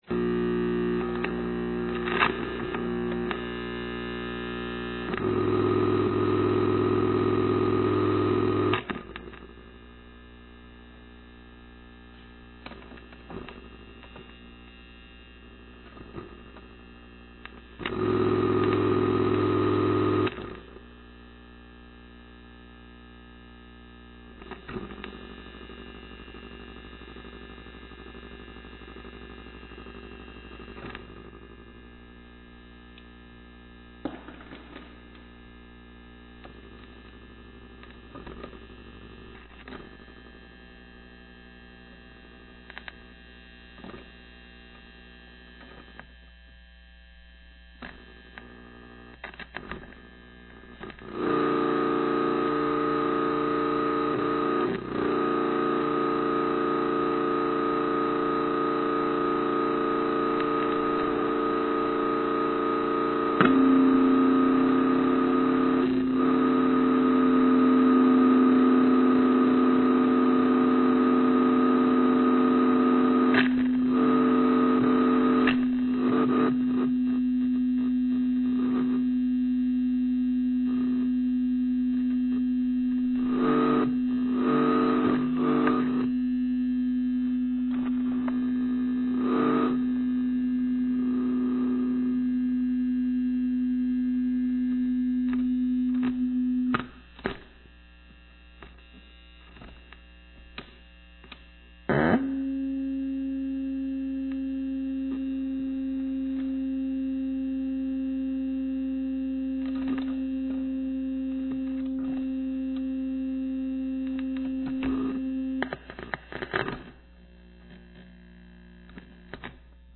Broadcasting from the Western Front Society in Vancouver
The sounds will be derived from spaces and objects at the Western Front, various electro-mechanical devices constructed by the artists, and feedback loops resulting from extreme amplification.
(a patchbay for acoustical feedback)
02_suitcase.mp3